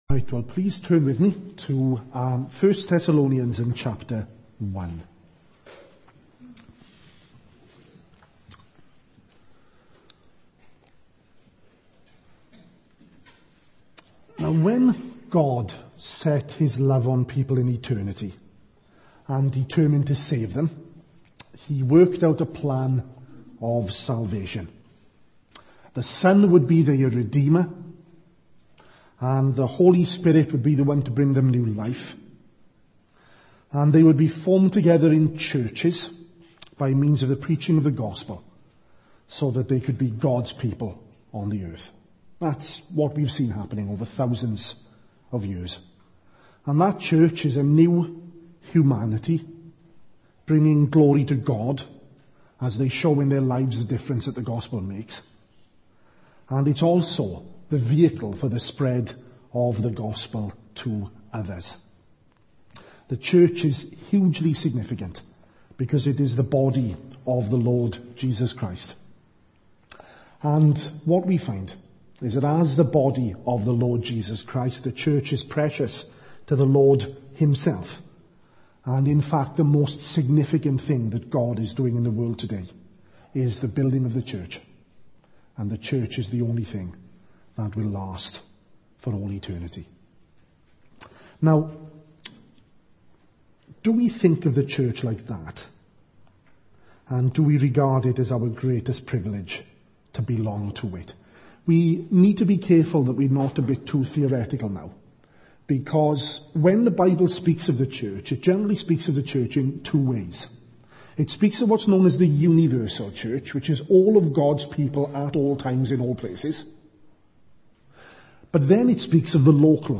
at evening service